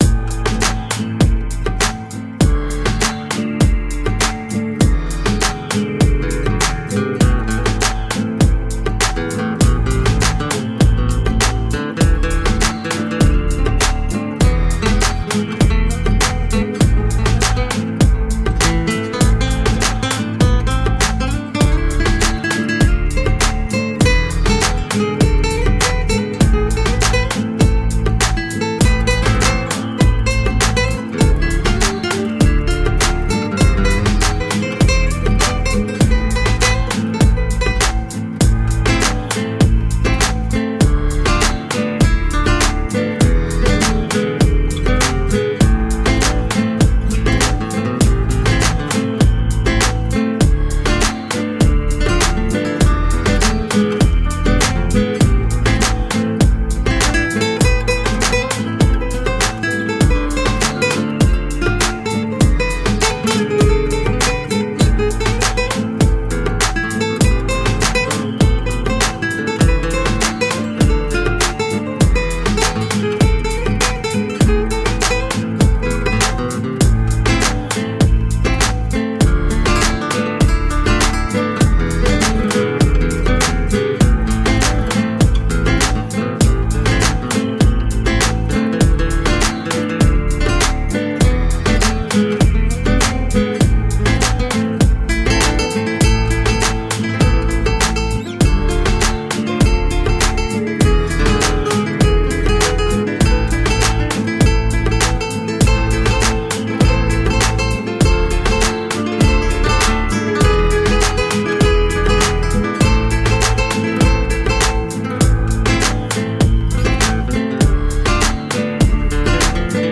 Urban flamenco.
Tagged as: World, Latin, World Influenced